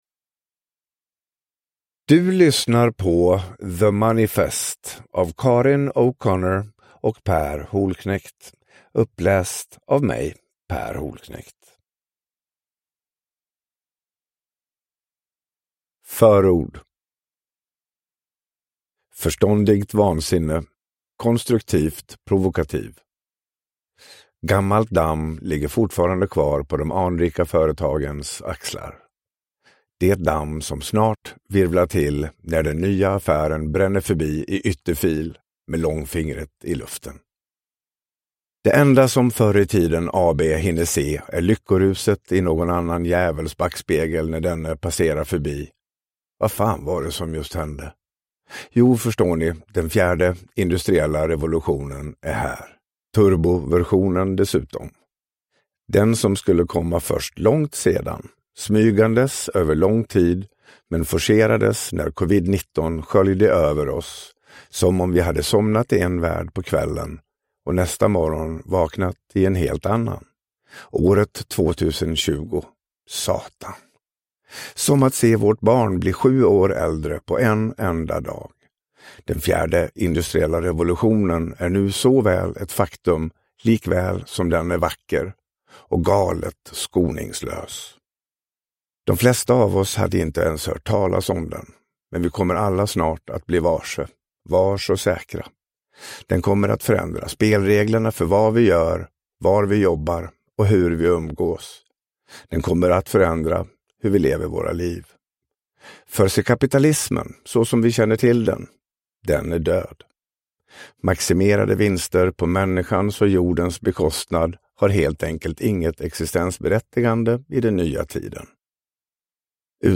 Uppläsare: Per Holknekt
Ljudbok